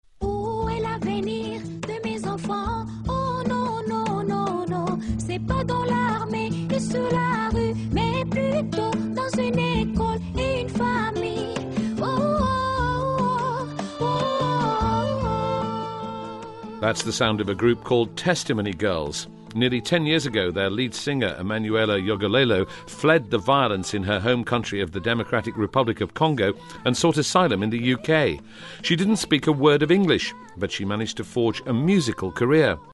【英音模仿秀】避难的音乐 听力文件下载—在线英语听力室